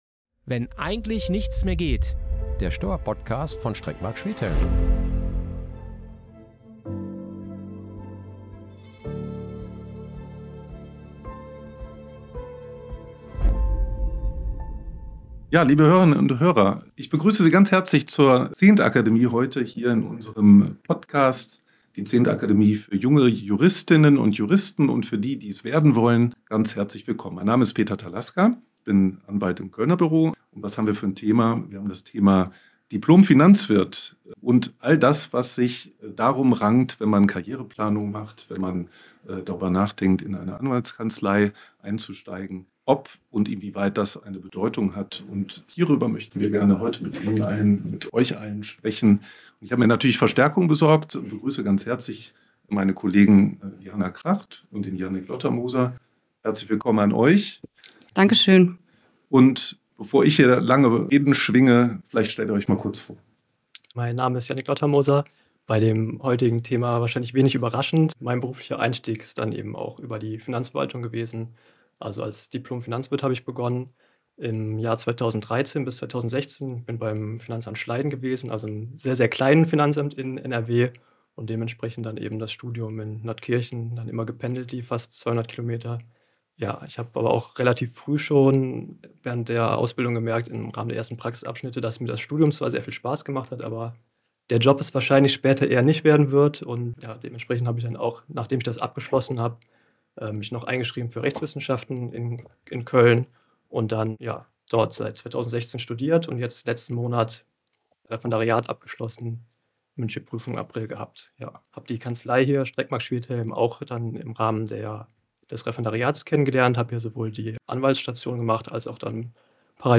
Die Teilnehmer:innen des Podcasts geben dabei spannende Einblicke – jeweils aus der Perspektive eines Partners, eines Associates und eines wissenschaftlichen Mitarbeiters.